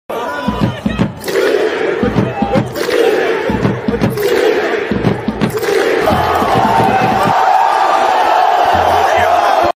Ronaldo claps whilst speed hits the drum
u3-Ronaldo-claps-whilst-speed-hits-the-drum.mp3